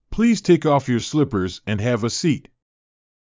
ﾌﾟﾘｰｽﾞ ﾃｲｸ ｵﾌ ﾕｱ ｽﾘｯﾊﾟｰｽﾞ ｴﾝﾄﾞ ﾊﾌﾞ ｱ ｼｰﾄ